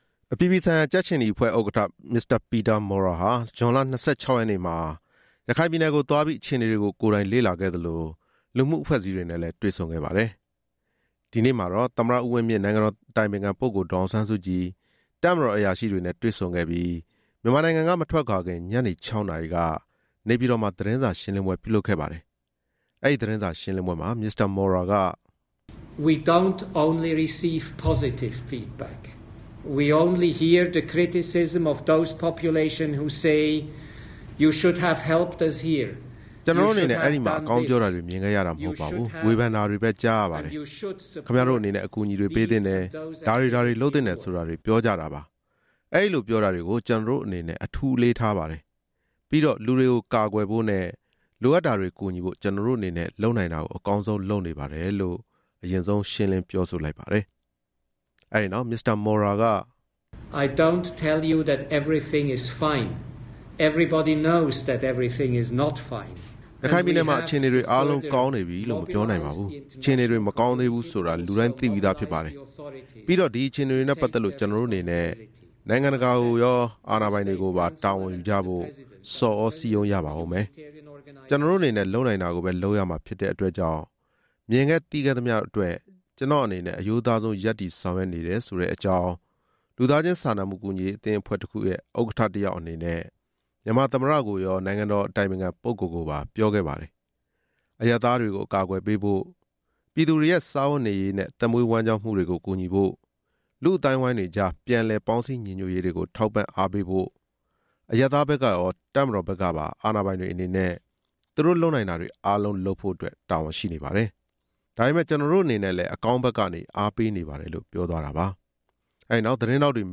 ICRC ဥက္ကဋ္ဌရဲ့ သတင်းစာရှင်းလင်းပွဲ